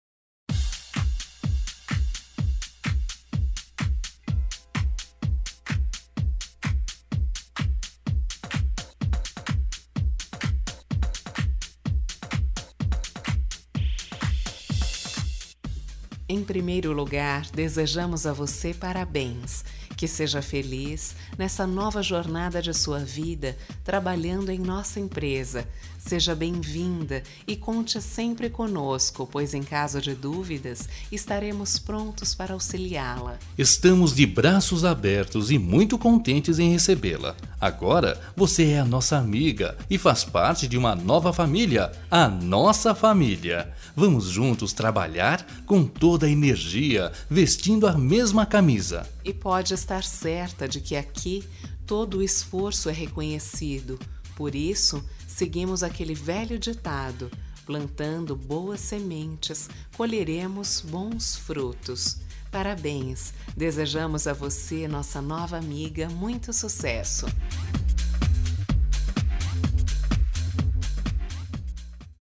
Duas Vozes